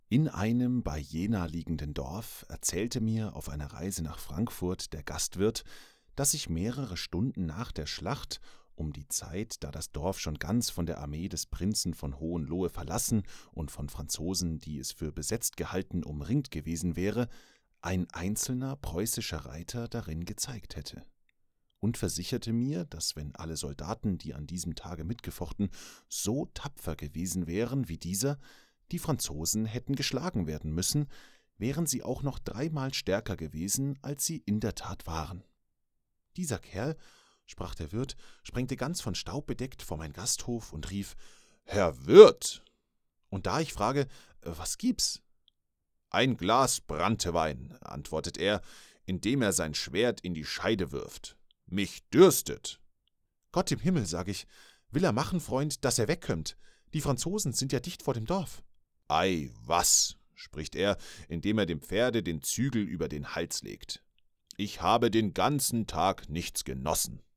Deutscher Sprecher fĂŒr Vertonungen aller Art. Meine Stimme ist tiefgehend, ruhig, klar und atmosphĂ€risch.
Sprechprobe: Sonstiges (Muttersprache):
Hörbuch_0.mp3